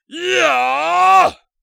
人声采集素材/男3战士型/ZS长声2.wav